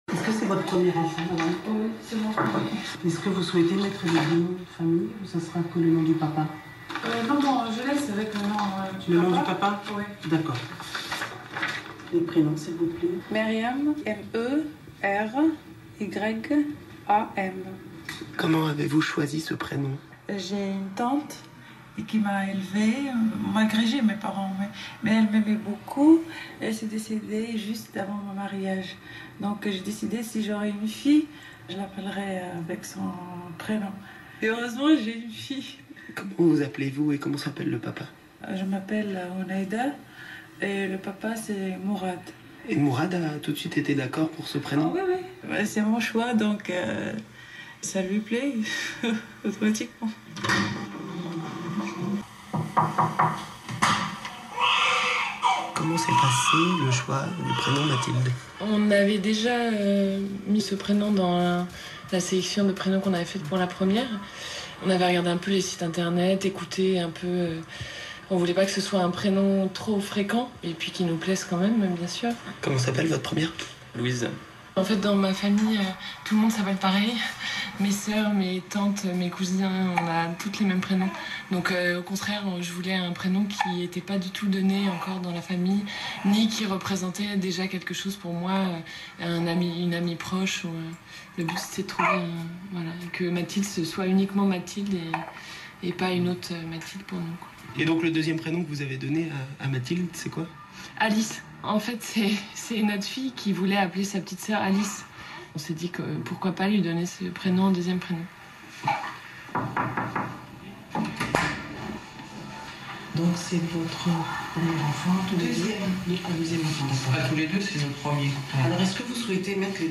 Petite balade dans une maternité, à la rencontre de bébés du printemps 2011.